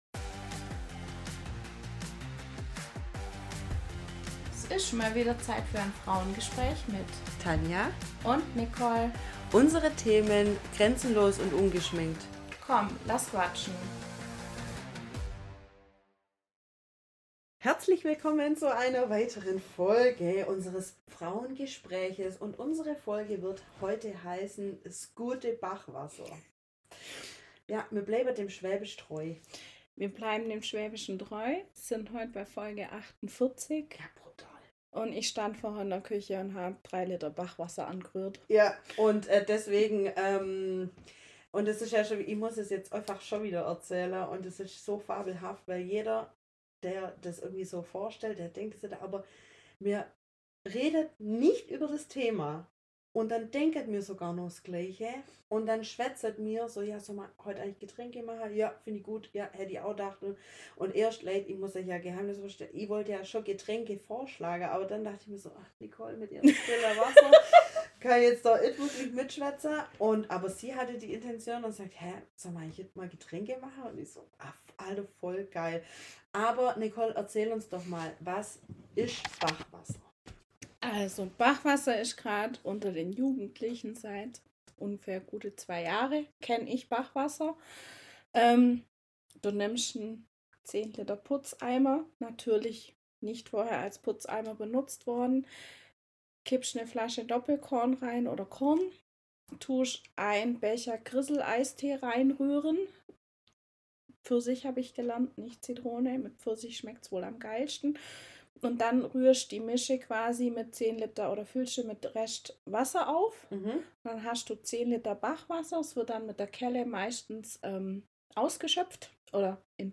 Liebste Zuhörerinnen, unser heutiges Frauengespräch-Podcastfolge lautet „S guade Bachwasser“ Wir schwätzen über diverse Getränkeunfälle und leckere Mischungen.